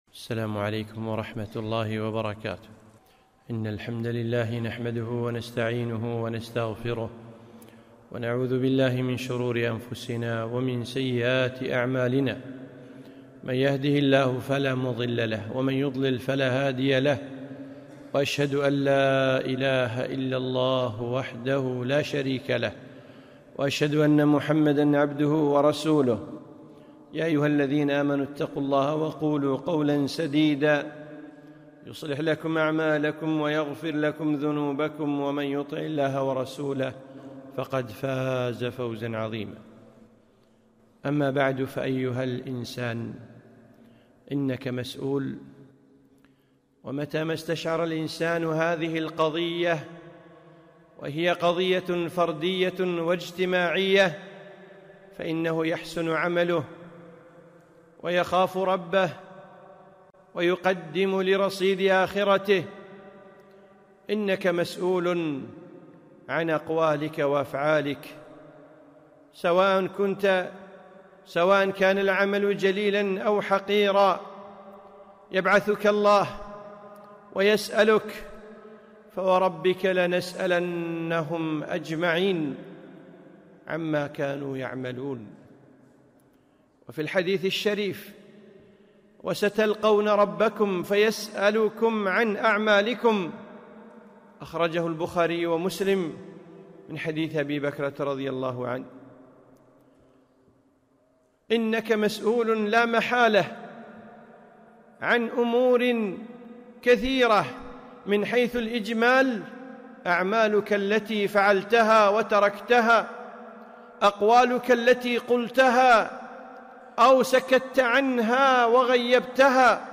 خطبة - إنك مسؤول